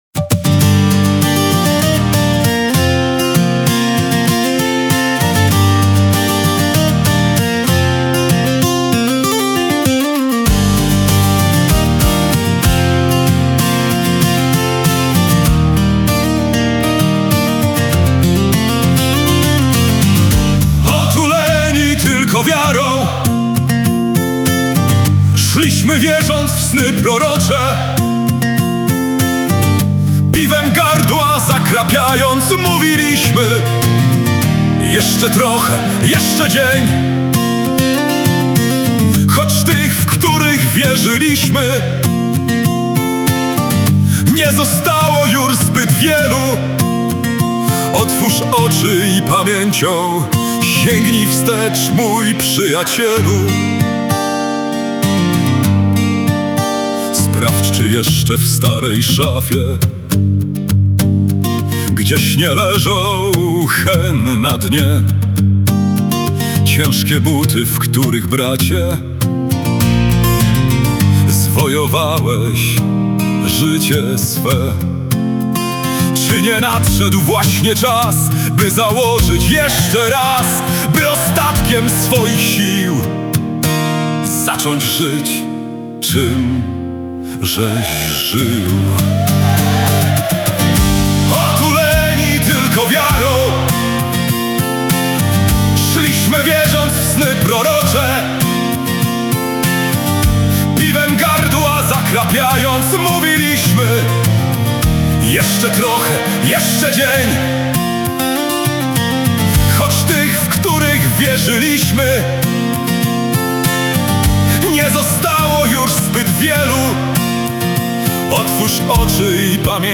linia melodyczna gitara
produkcja AI.